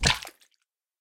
Minecraft Version Minecraft Version 25w18a Latest Release | Latest Snapshot 25w18a / assets / minecraft / sounds / mob / guardian / land_hit1.ogg Compare With Compare With Latest Release | Latest Snapshot
land_hit1.ogg